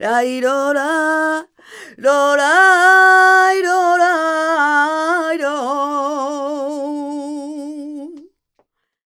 46a04voc-c#.wav